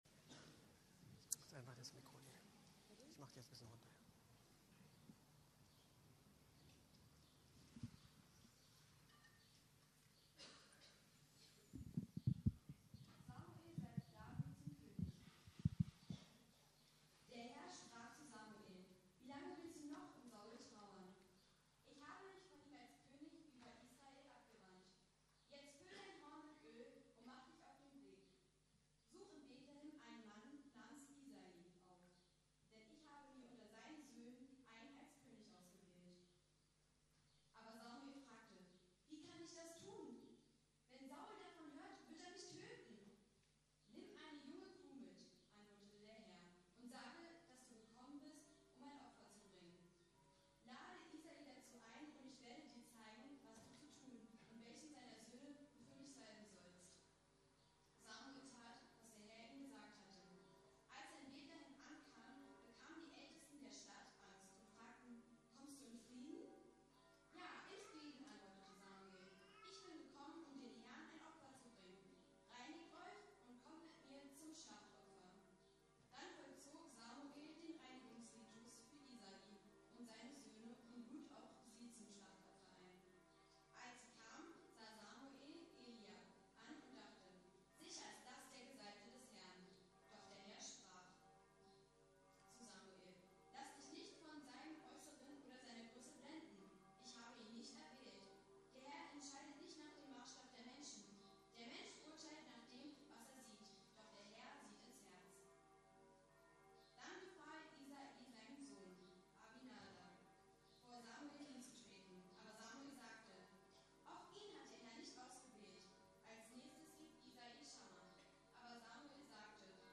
Unsere Berufung neu entdecken: Plötzlich berufen ~ Predigten der LUKAS GEMEINDE Podcast